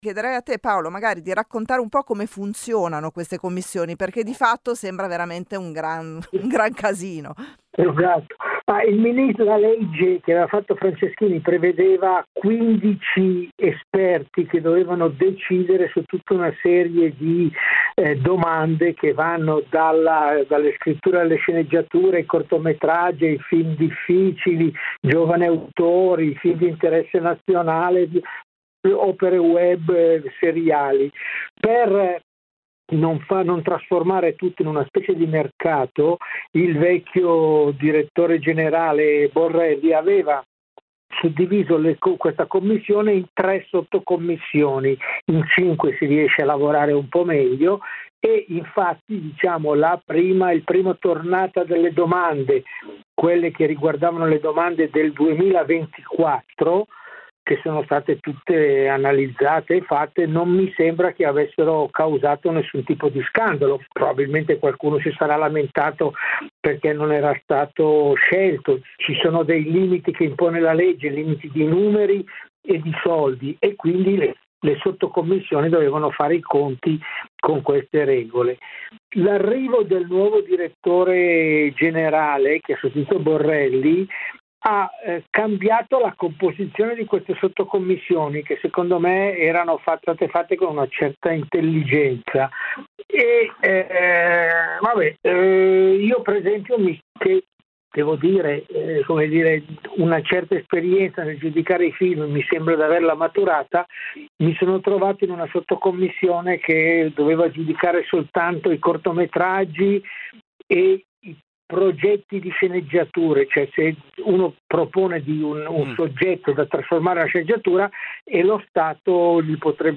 Spiega Mereghetti al microfono di Radio Popolare e aggiunge “Credo di essere stato nominato per la mia esperienza nel campo della critica, ma se i progetti di qualità vengono sacrificati per una sorta di snobismo populista, non ha senso restare nella sottocommissione in cui ero stato relegato.